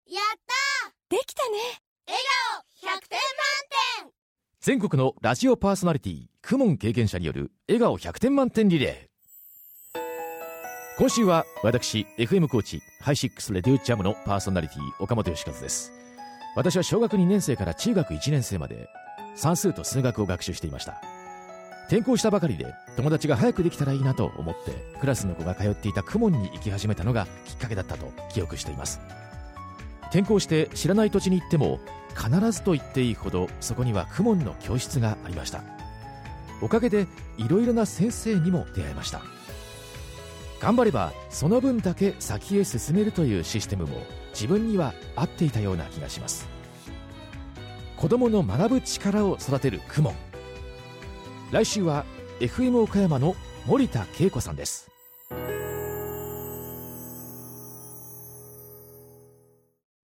「子どもの頃、KUMONやってました！」 「今、子どもが通っています！」･･･という全国のパーソナリティのリアルな声をお届けします。